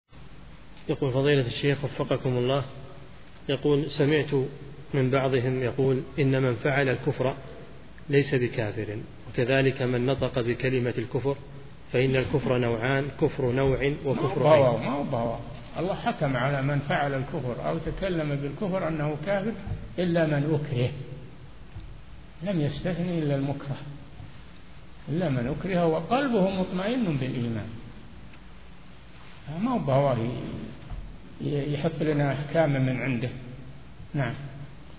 Réponse du Shaykh qui coupe la question :